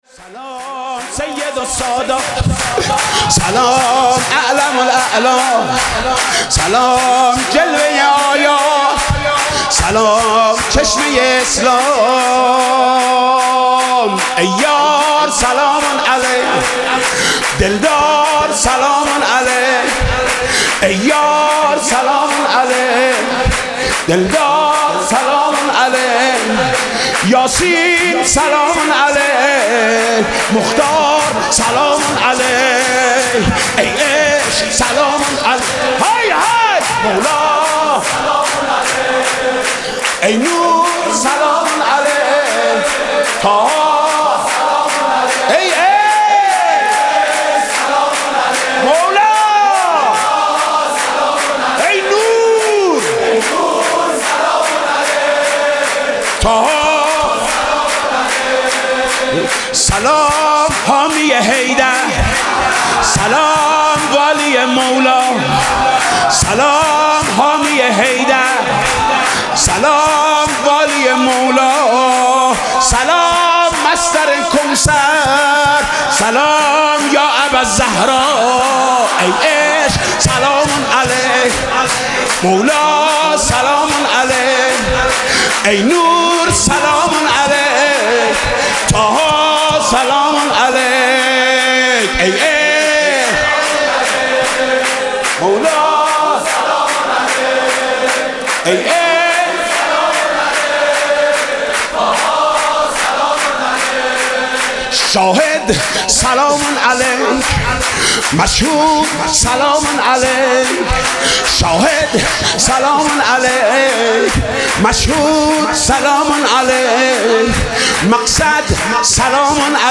سرود: سلام سید السادات